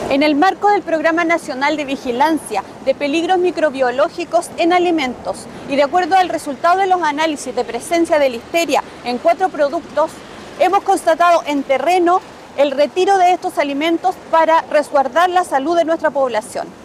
Además, se está investigando el origen de la contaminación para tomar las medidas correctivas correspondientes, como explicó la Seremi de Salud de Los Lagos, Karin Solís.